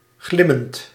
Ääntäminen
IPA: /blʲɪˈsʲtʲæɕːɪj/